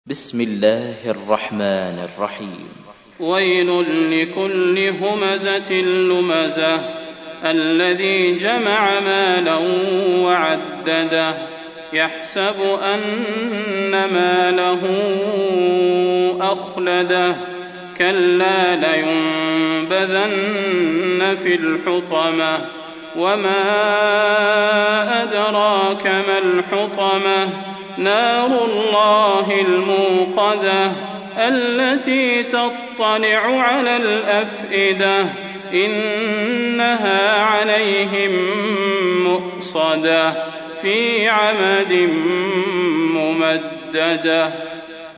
تحميل : 104. سورة الهمزة / القارئ صلاح البدير / القرآن الكريم / موقع يا حسين